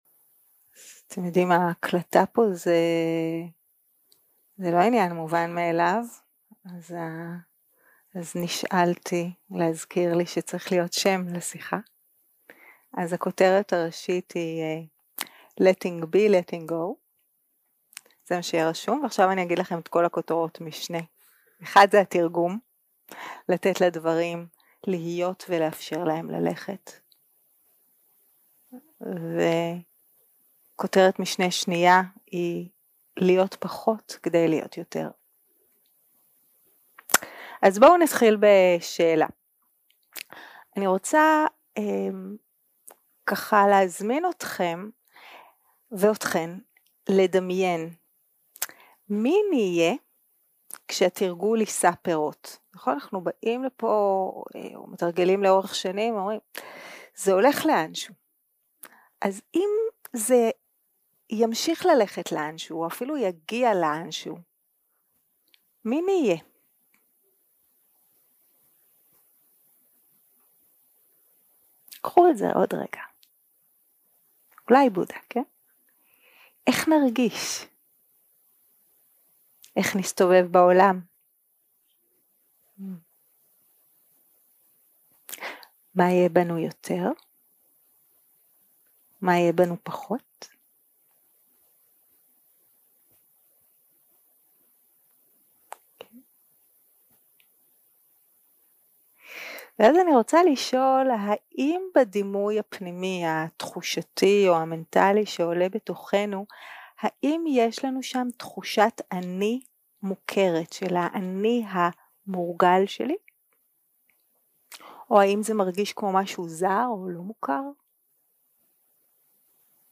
יום 3 - הקלטה 7 - ערב - שיחת דהרמה - Letting be, letting go
Dharma Talks שפת ההקלטה